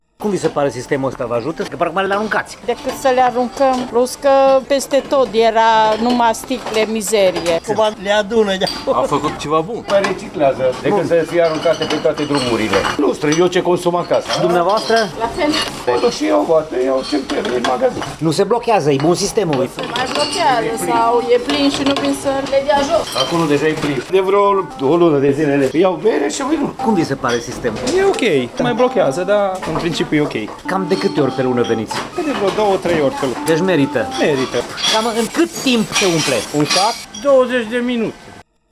Cetățenii par mulțumiți de sistem, însă ei sesizează că nu la toate magazinele din Tg. Mureș există astfel de automate care să acorde vouchere la schimb, astfel că cele existente se umplu repede: